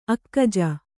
♪ akkaja